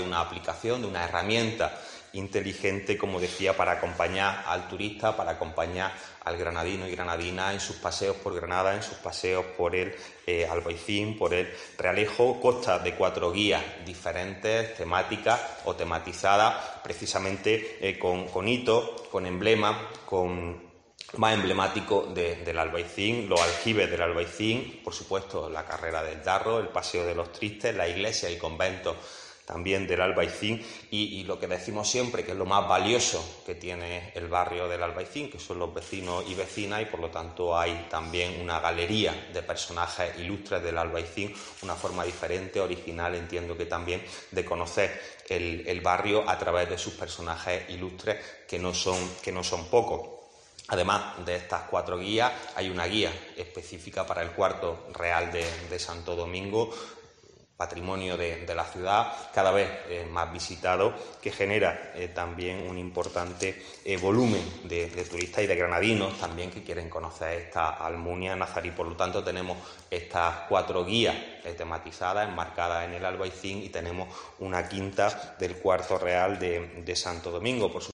Eduardo Castillo, concejal de comercio y turismo